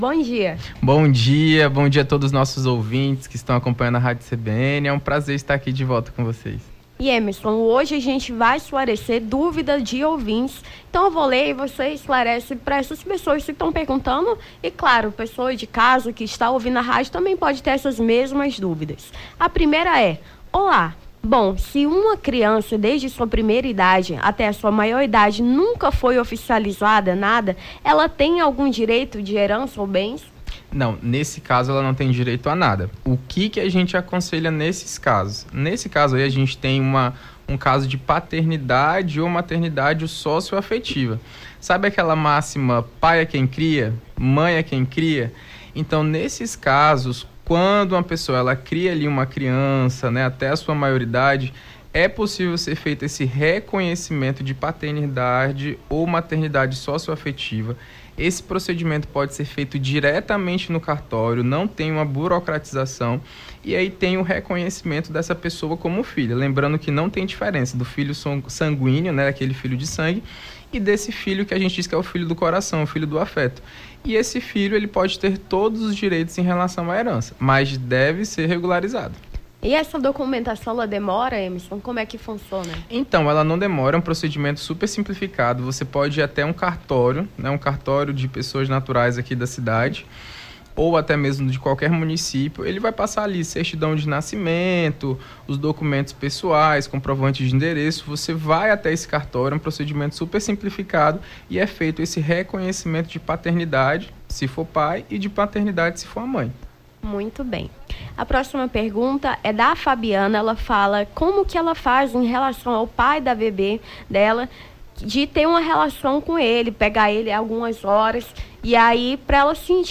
Nome do Artista - CENSURA - ENTREVISTA (JULHO LARANJA) 20-07-23.mp3